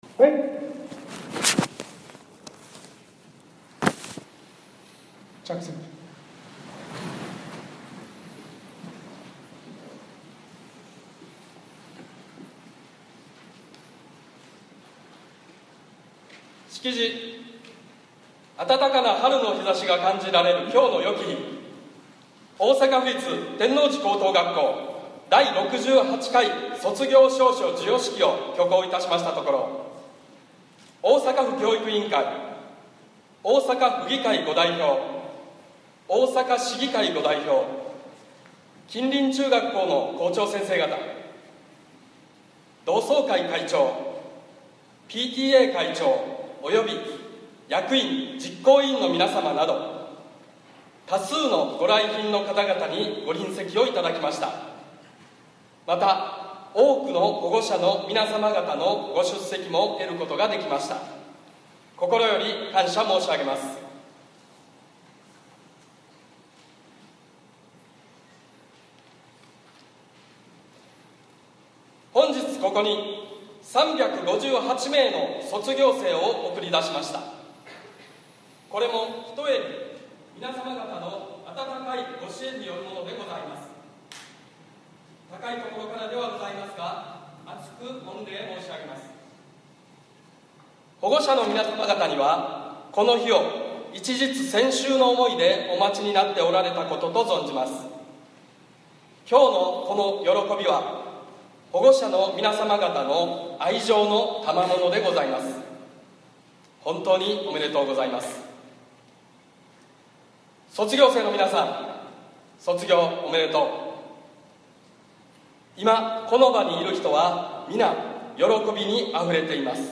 web-convert27Graduationceremony.mp3